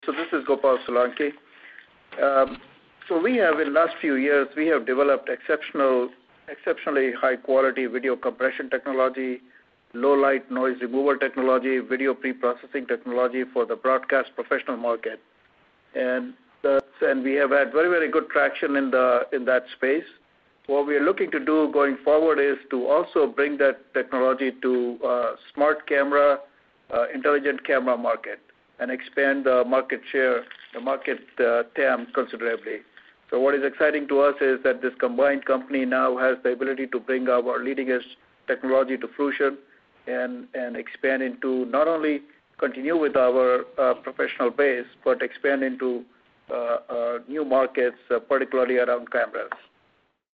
tells analysts about the focus of the company during the April 4, 2016 call announcing the company's acquisition by GigOptix.